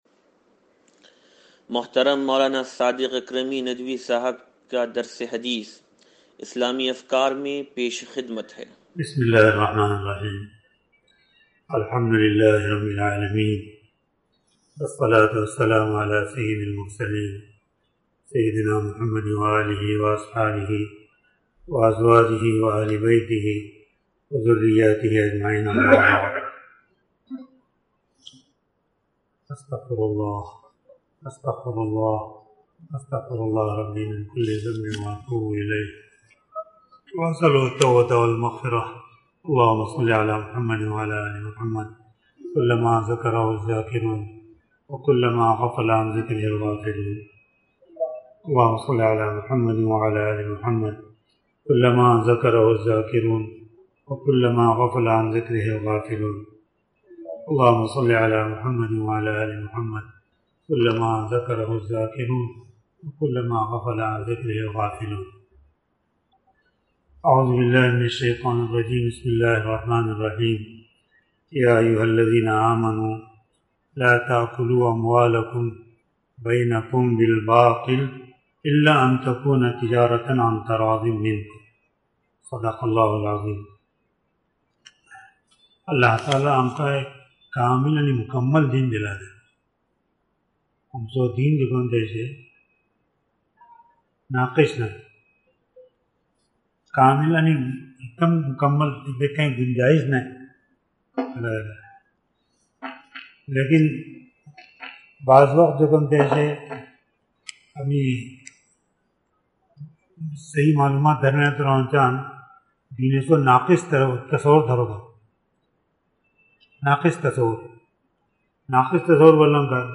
درس حدیث نمبر 0538